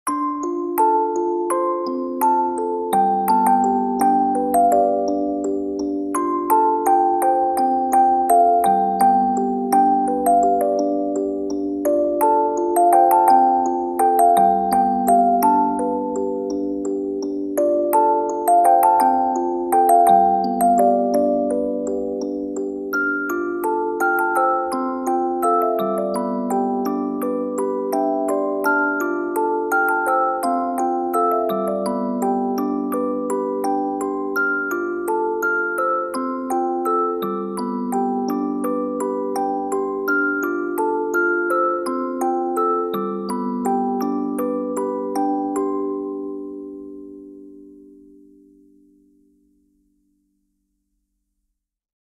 Music Box